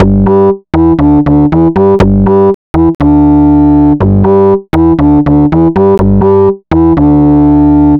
Astro 4 Bass-G.wav